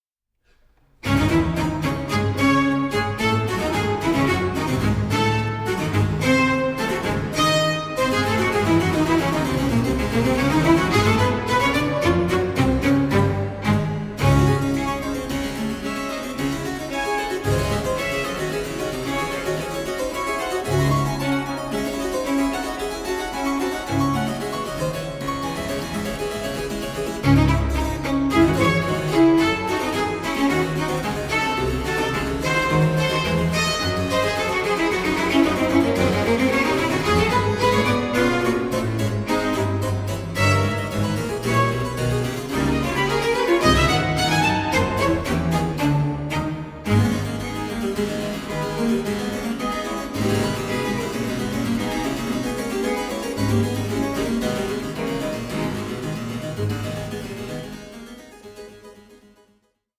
(48/24, 88/24, 96/24) Stereo  14,99 Select